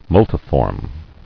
[mul·ti·form]